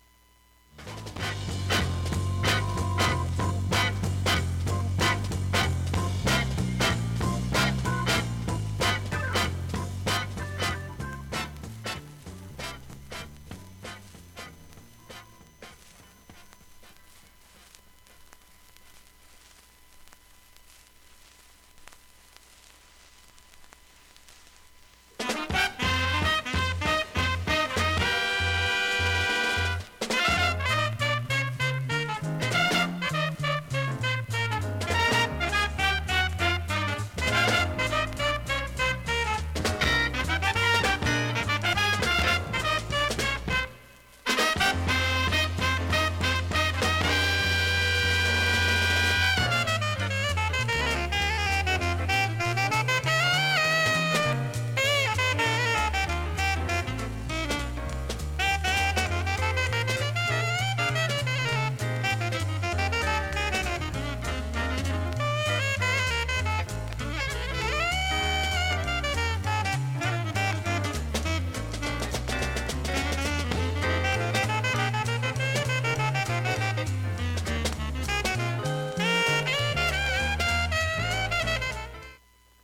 音質良好全曲試聴済み。
B-3終りフェイドアウト部からB-4序盤に
スレで70秒の間にプツ出ますが
ほんのかすかなレベルです。
JBがオルガンを弾きまくるインストアルバム。
ドラムとギターがかなりかっこいい。
MOD系統のBOOGALOO FUNKY JAZZ